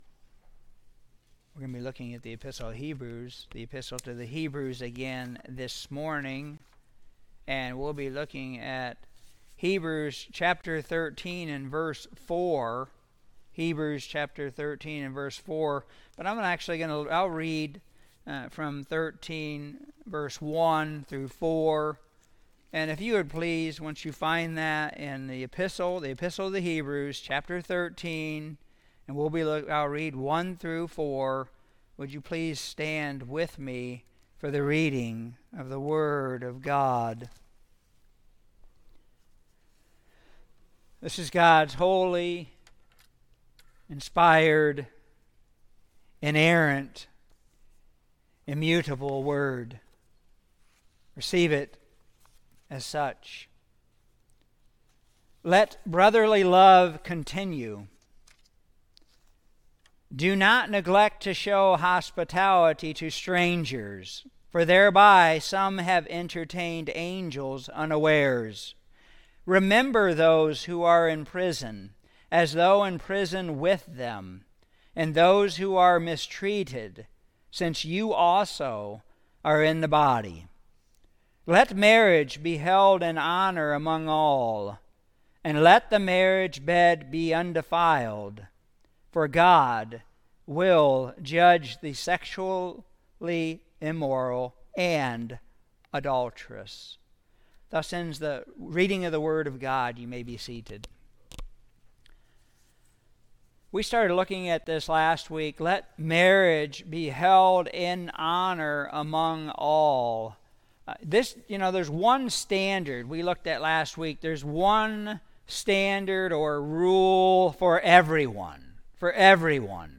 Sermons | Berean Reformed Baptist Church